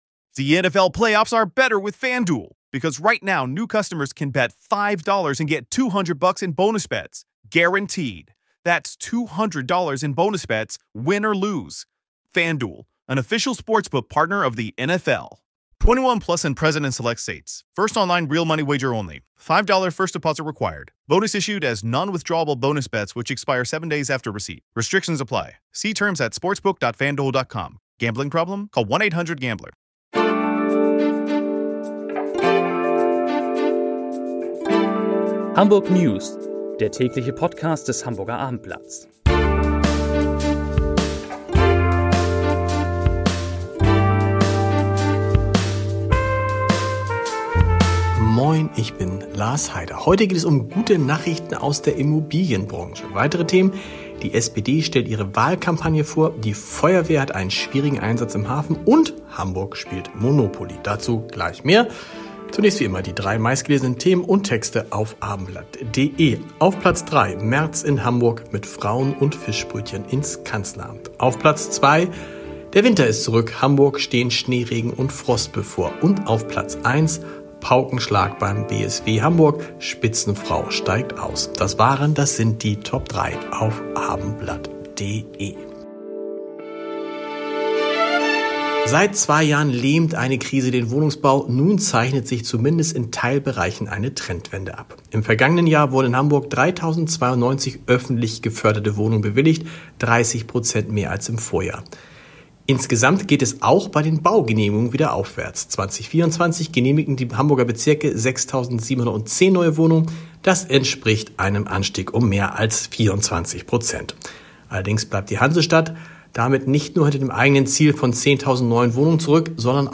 HH-News – Ihr täglicher Nachrichten-Flash aus Hamburg.